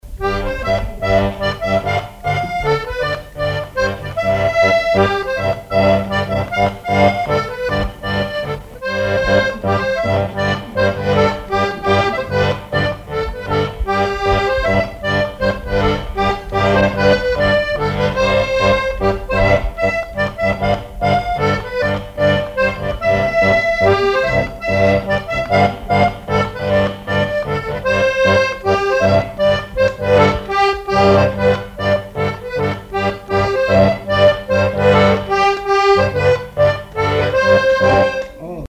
Chants brefs - A danser
danse : mazurka
Chansons et répertoire du musicien sur accordéon chromatique
Pièce musicale inédite